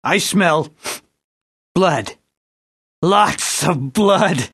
Hlášok je nepreberné množstvo a sú fakt kvalitne nadabované, intonácia a výber hlasov sedia presne do situácií, kedy postava povie svoju repliku.